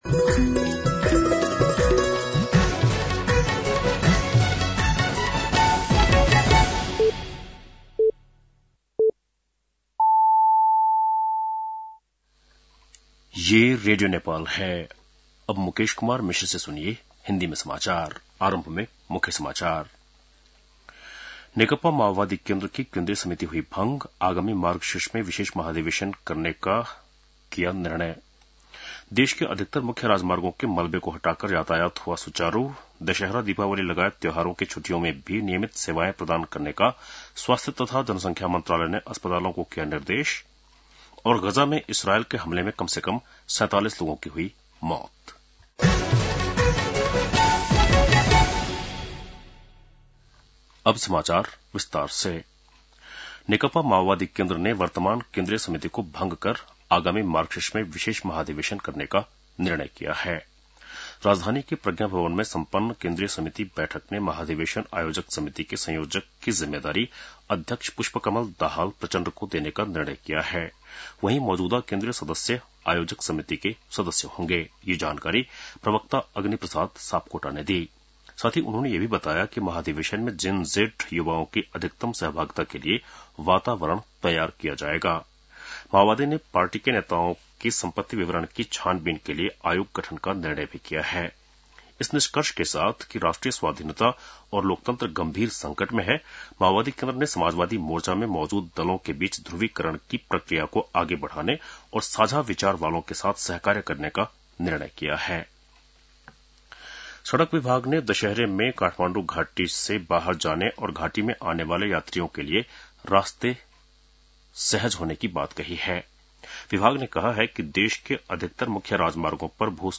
बेलुकी १० बजेको हिन्दी समाचार : १० असोज , २०८२